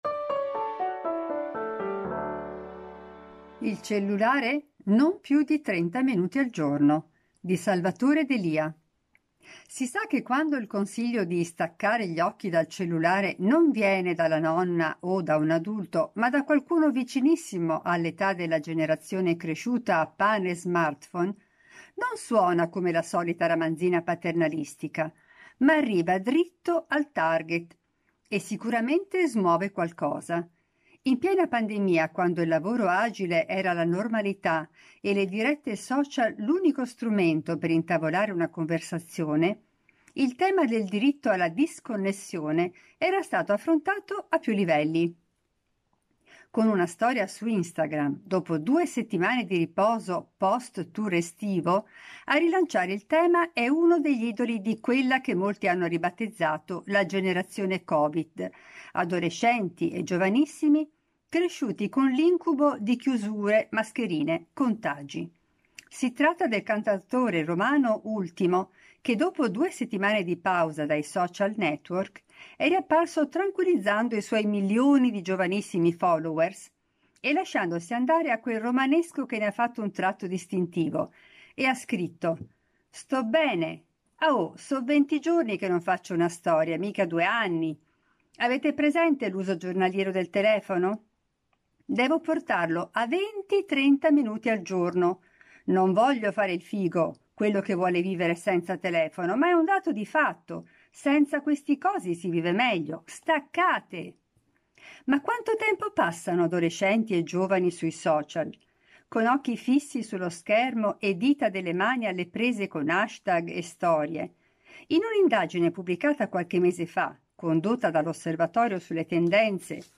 Al microfono, i nostri redattori e nostri collaboratori.